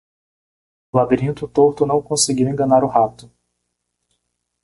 Pronunciado como (IPA)
/la.biˈɾĩ.tu/